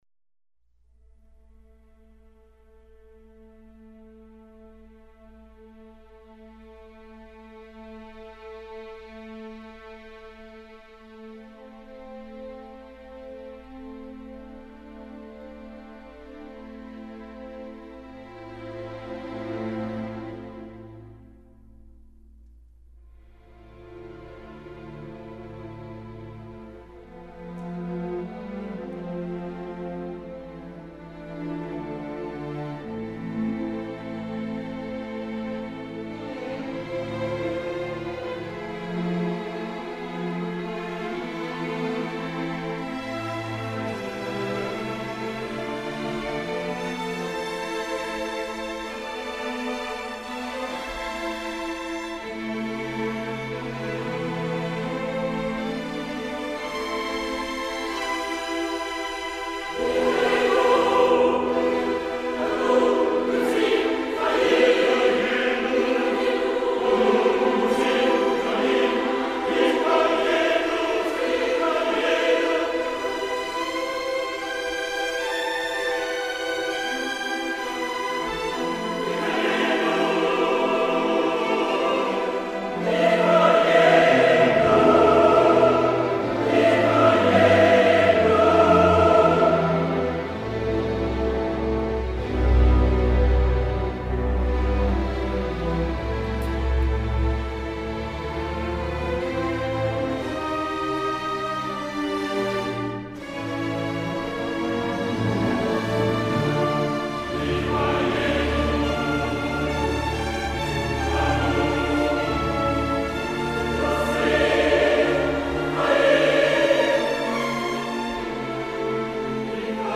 采用了小提琴独奏的方式突出主题